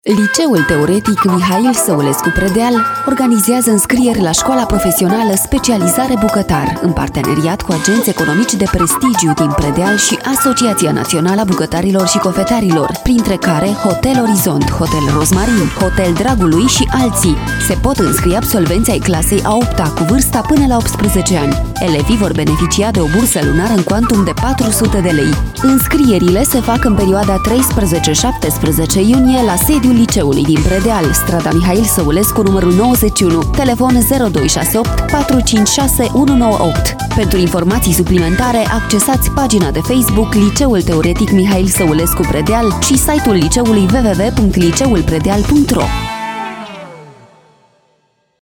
Spot publicitar